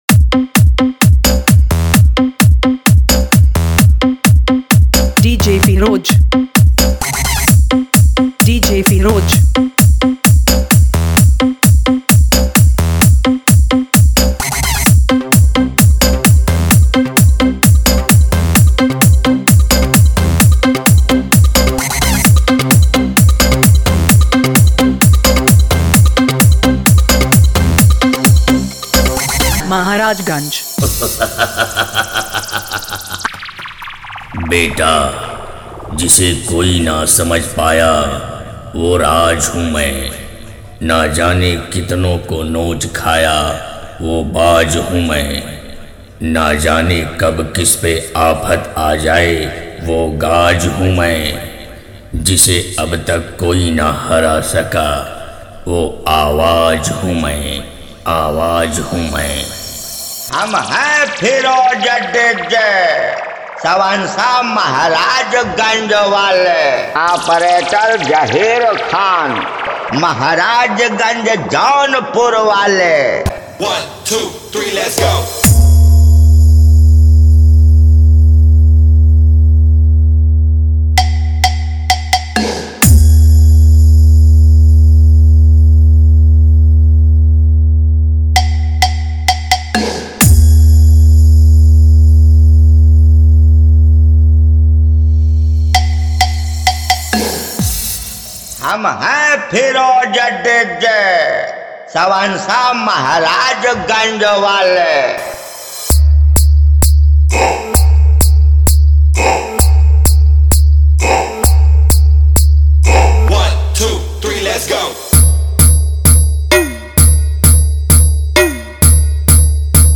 Category: Competition All Top Mix Zone